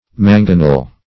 Mangonel \Man"go*nel\, n. [OF. mangonel, LL. manganellus,